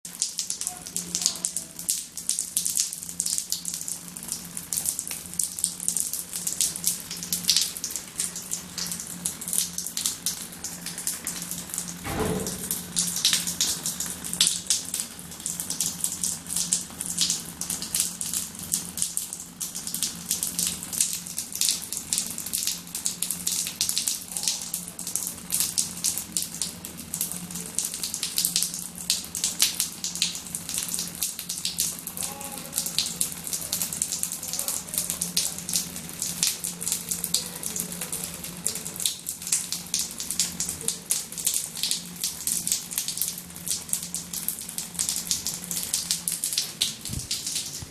Location: Estabrook Bathroom Shower
Sounds Heard: Water falling on shower floor, guys yelling excitedly in the background
waterfromthe-shower1.mp3